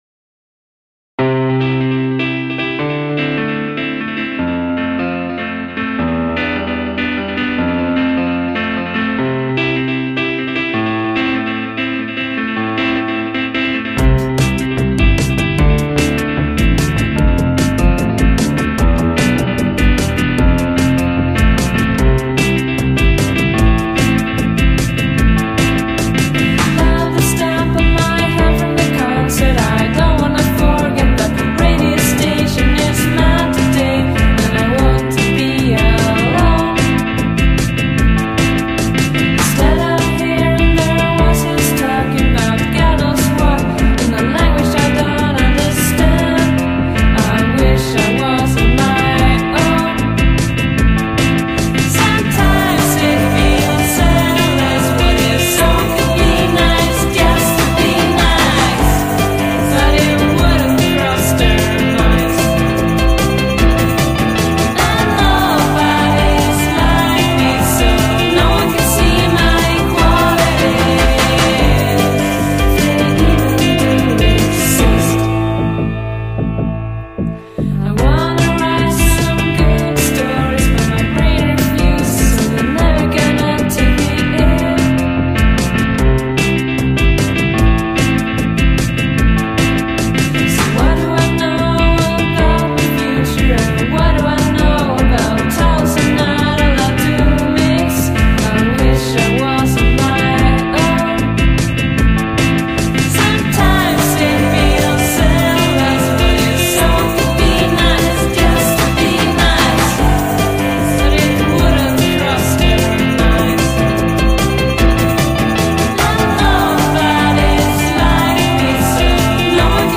vocals and guitar
bass, keyboard and vocals